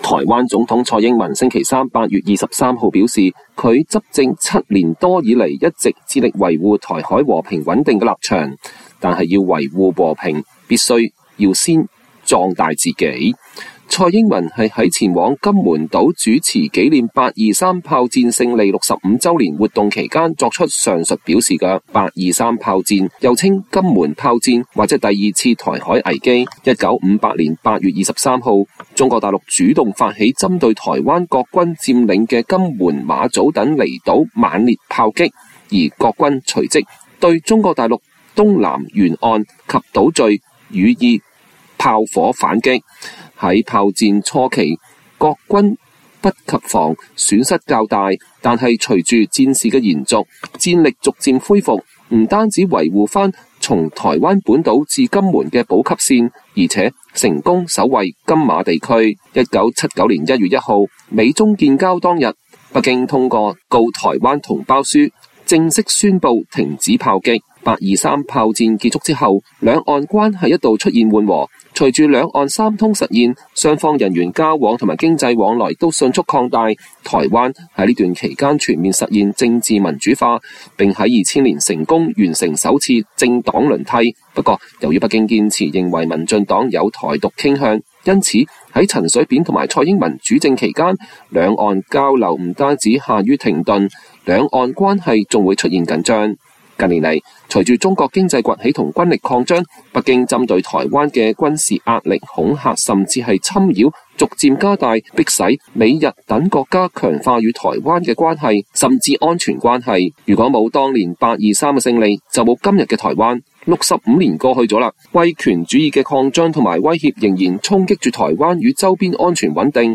蔡英文“823”演講：只有“同島一命”才能守住家園